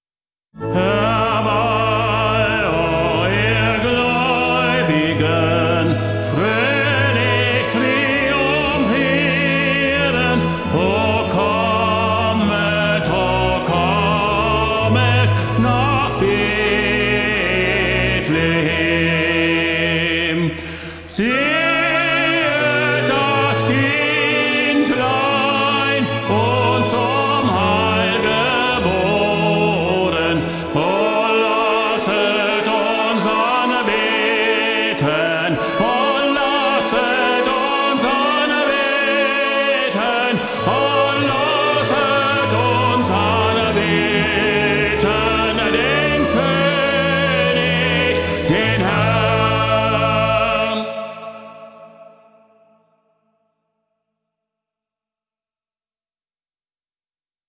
Weihnachtliche Lieder aus 5 Jahrhunderten und in 5 Sprachen Ein Konzert wie eine magische Reise auf dem Schlitten des Weihnachtsmannes…
Herbei-Oh-Ihr-Glaeubigen-Tenor.wav